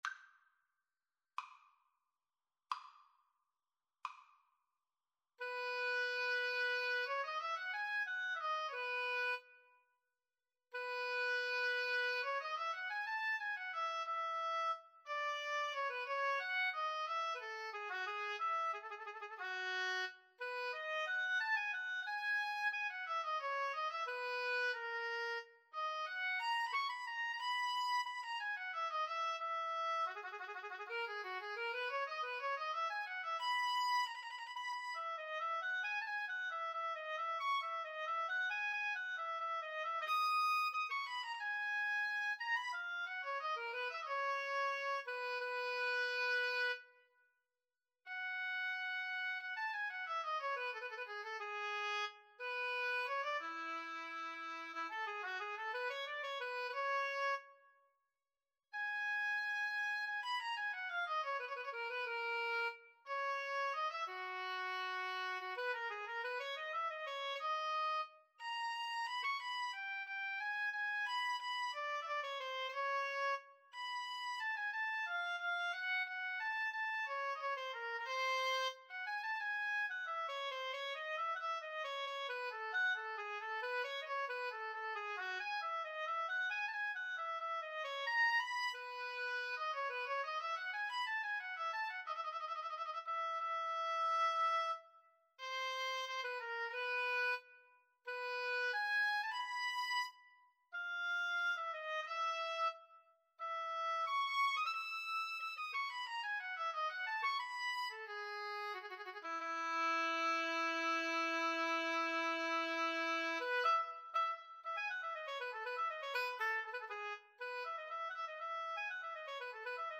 Free Sheet music for Flute-Cello Duet
4/4 (View more 4/4 Music)
E major (Sounding Pitch) (View more E major Music for Flute-Cello Duet )
Classical (View more Classical Flute-Cello Duet Music)
flute
harpsichord